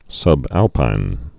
(sŭb-ălpīn)